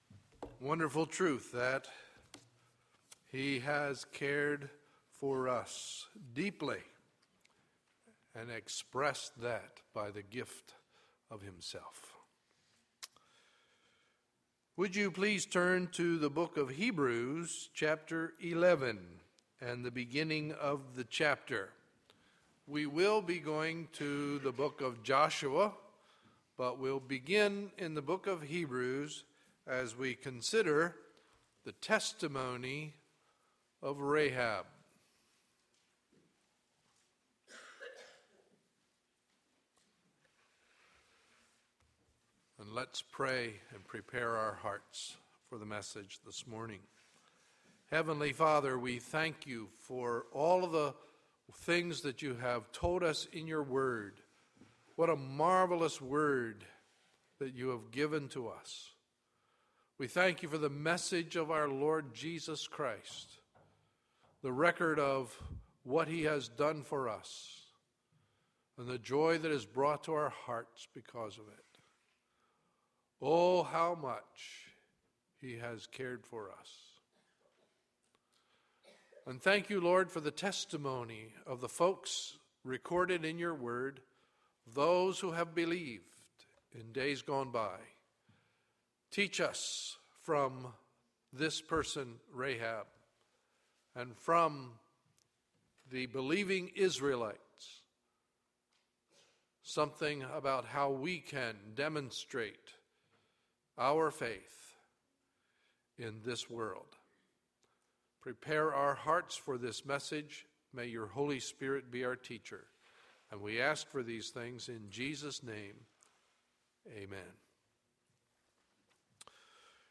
Sunday, April 22, 2012 – Morning Message